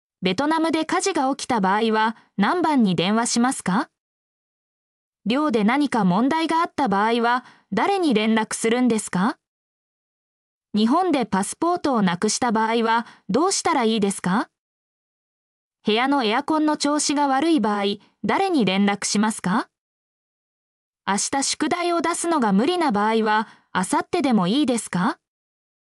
mp3-output-ttsfreedotcom-17_tfrEpQ10.mp3